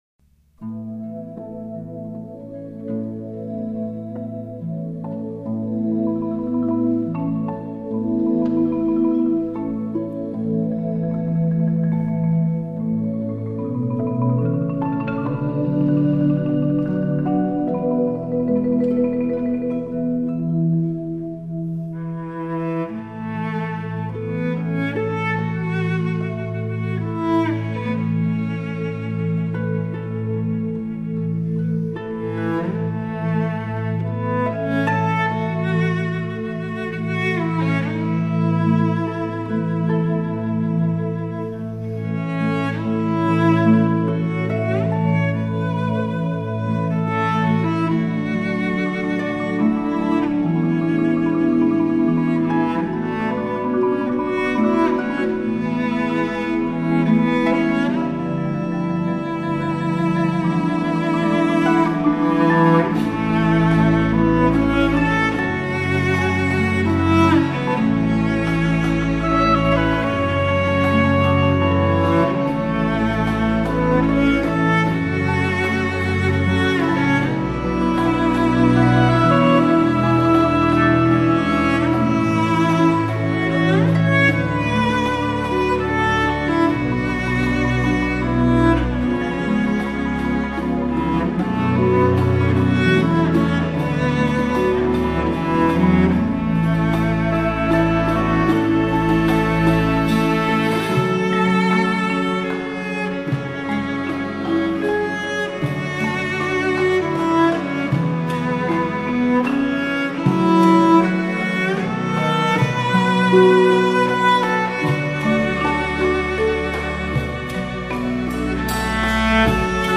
在编曲上，除了大提琴绝美的哦吟，还很有创意地加入了各种打击乐 器。